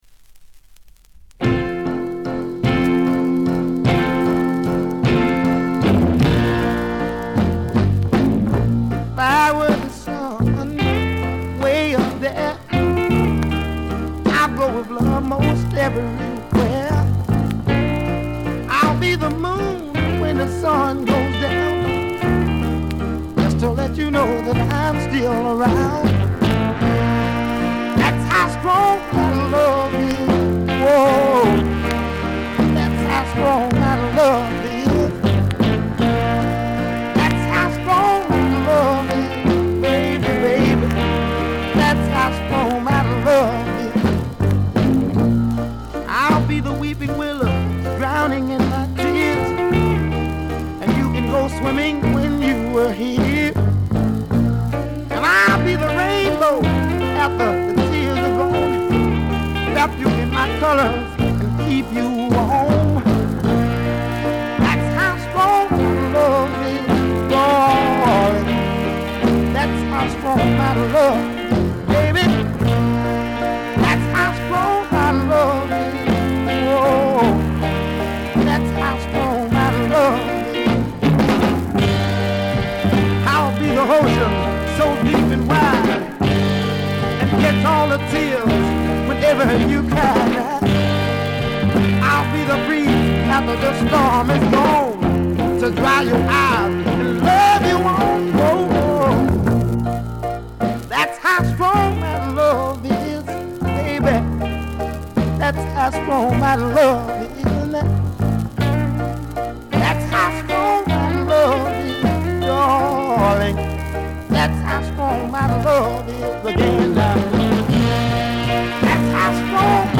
バックグラウンドノイズ（A2序盤が特に目立つ）、チリプチ。
モノラル盤。
試聴曲は現品からの取り込み音源です。
tenor saxophone
baritone saxophone